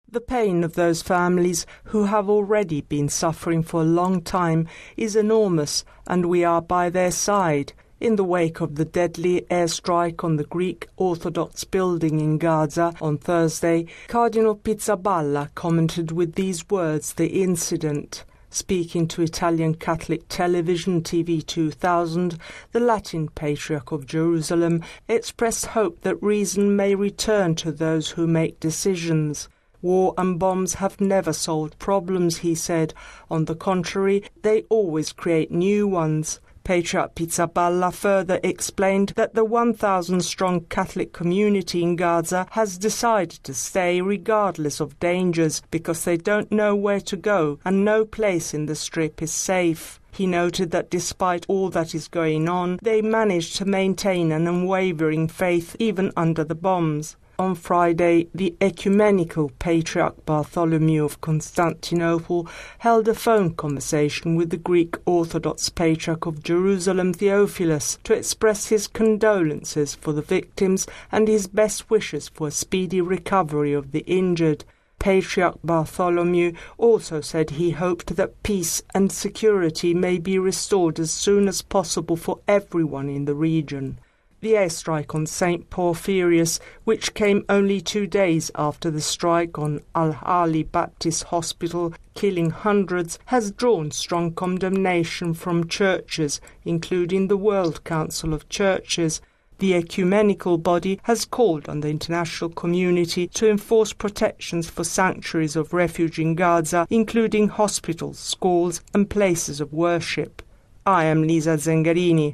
Tg2000’s interview with Cardinal Pierbattista Pizzaballa, Custos of the Holy Land, was an opportunity to hear the testimony of a man who has been working in the region for more than 20 years.